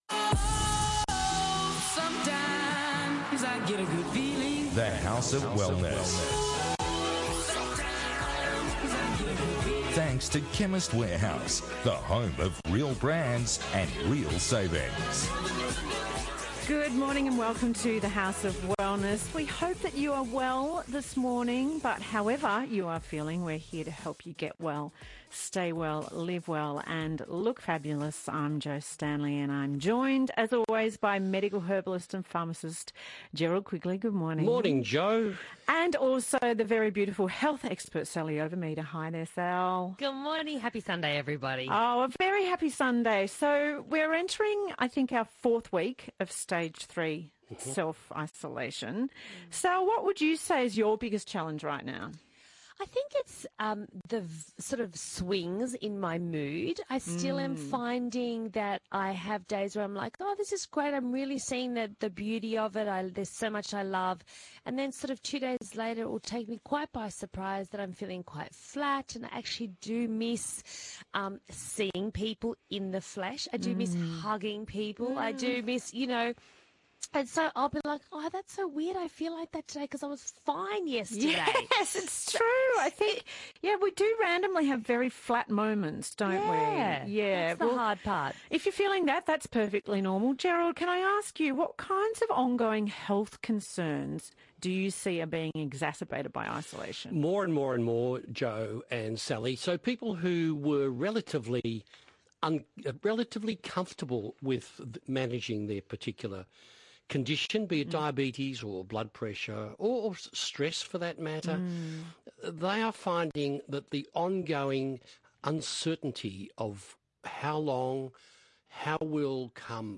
Get well, stay well, live well and look fabulous with The House of Wellness radio show.